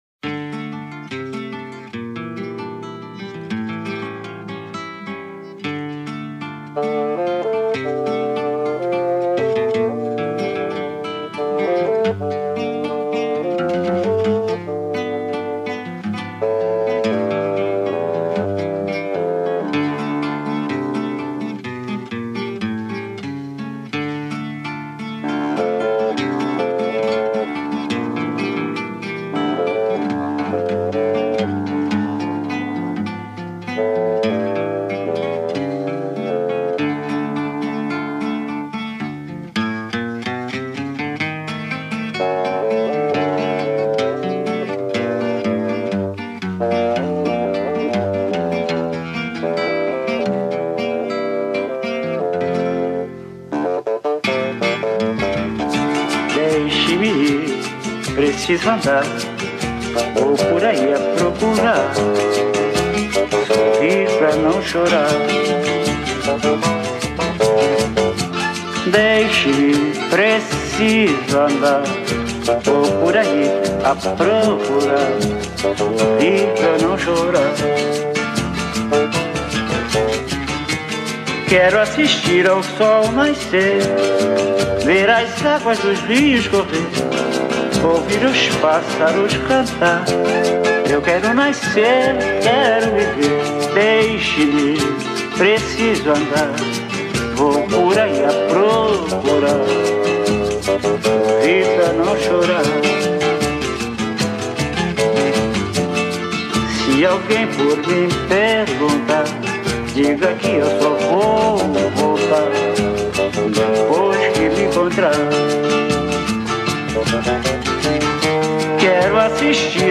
2025-02-23 01:17:03 Gênero: MPB Views